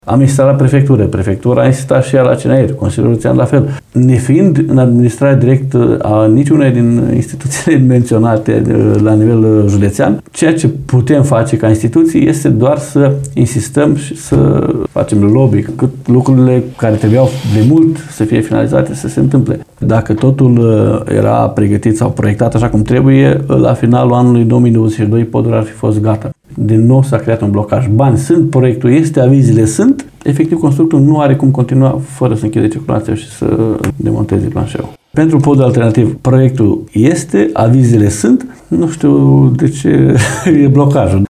El ne-a declarat, în exclusivitate, că podul este proprietatea Companiei Naționale de Administrare a Infrastructurii Rutiere, prin Direcția Regională de Drumuri și Poduri Iași, care trebuie să deruleze proiectul.